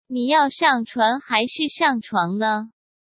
Nǐ yào shàng chuán hái shì shàng chuáng ne?